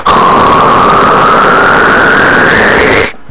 1 channel
rocket.mp3